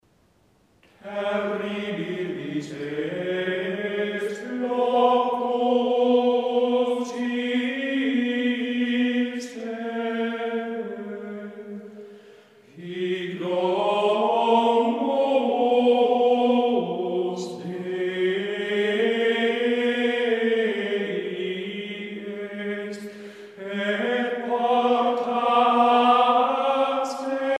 Musique médiévale